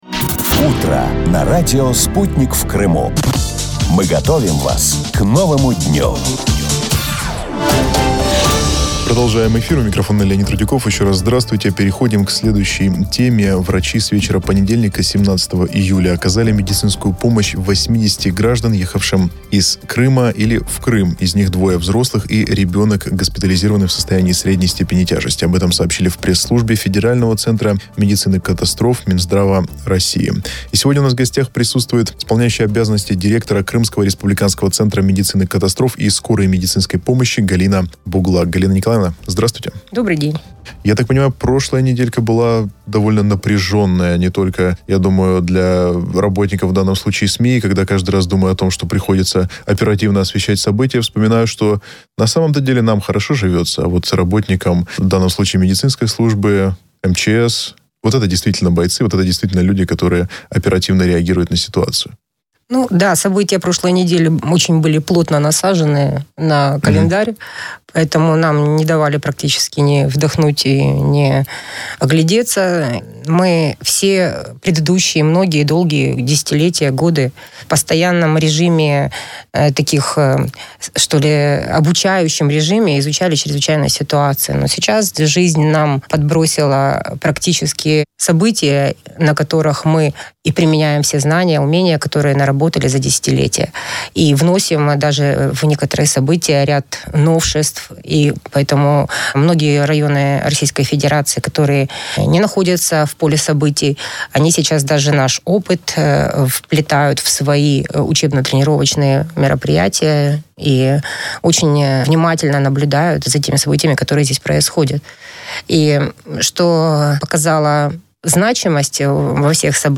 Слушайте запись эфира.